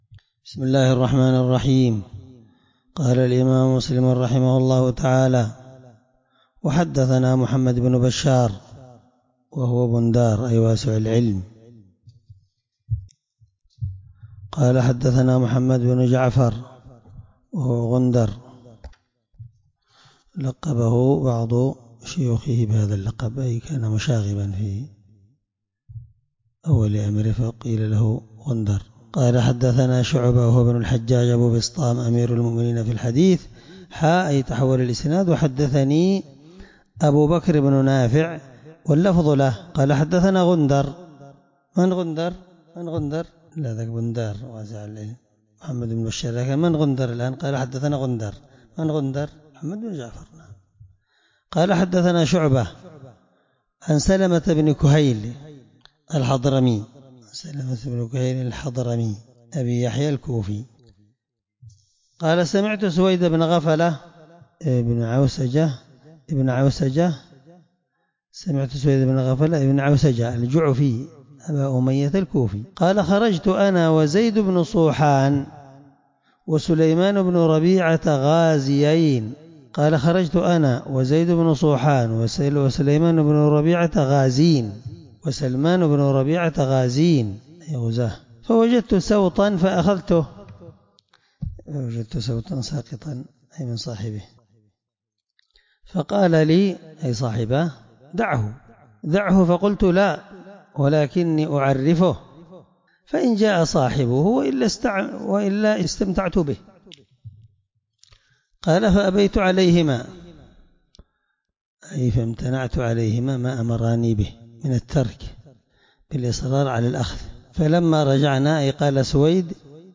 الدرس2من شرح كتاب اللقطة الحدود حديث رقم(1723) من صحيح مسلم